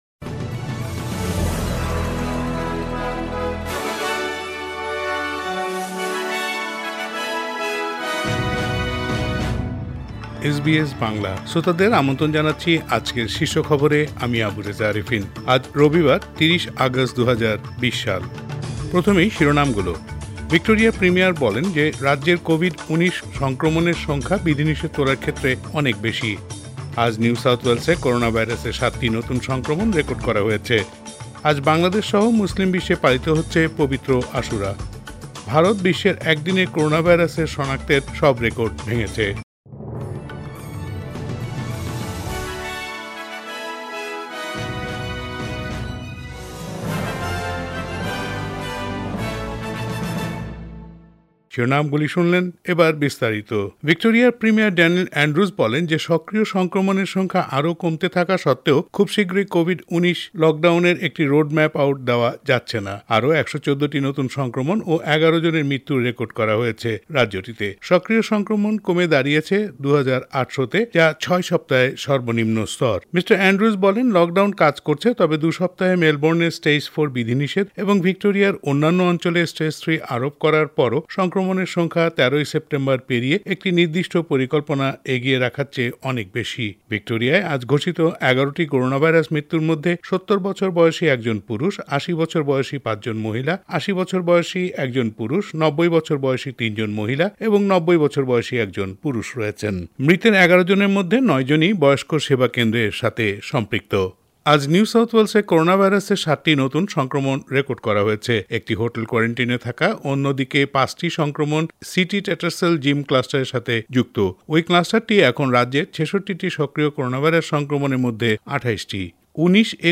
এসবিএস বাংলা শীর্ষ খবর ৩০ আগস্ট ২০২০